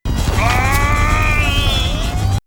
yell Ryu makes when he gets caught in the net is the same yell Zangief made in "The Flame and the Rose".
ryu yell.mp3